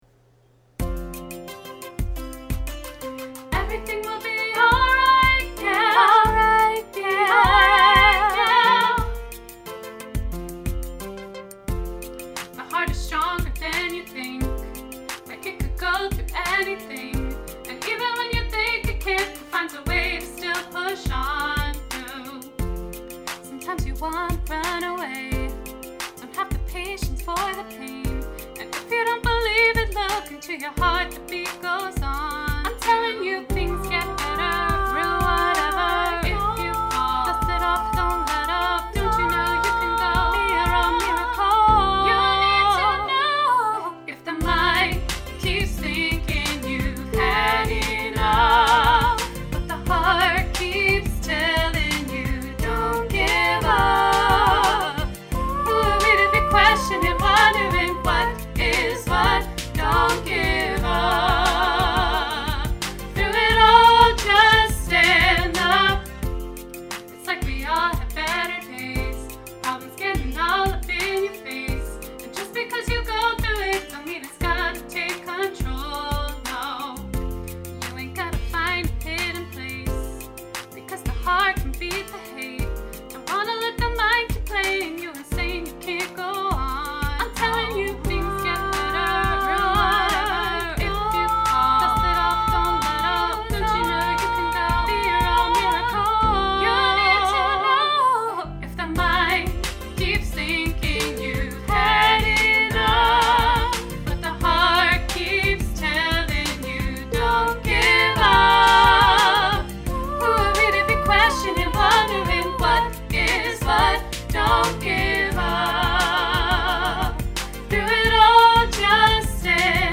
You will hear 3 parts.
You will hear my voice singing all three parts.